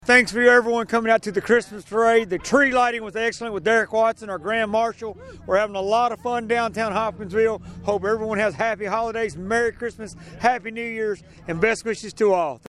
Mayor Knight thanked the community for turning out and celebrating the season together.